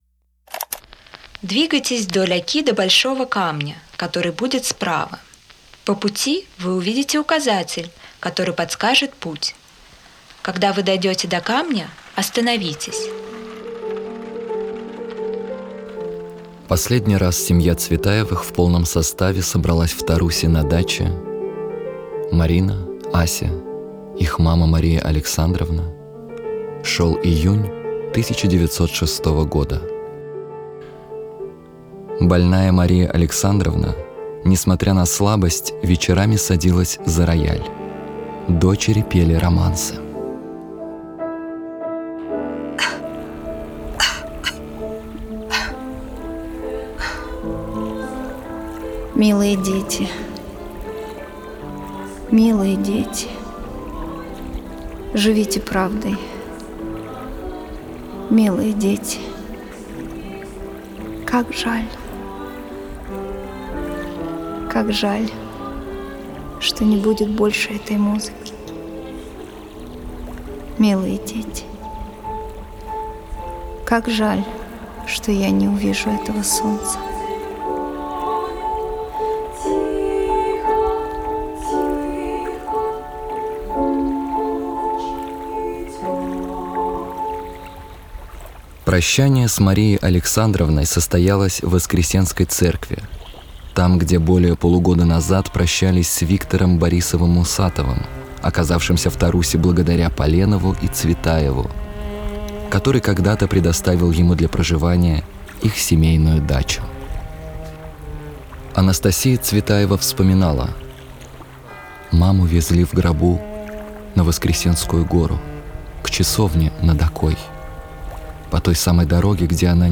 Аудиоспектакль-променад по Тарусе «Память любви. Непридуманные истории, случившиеся на берегах Оки»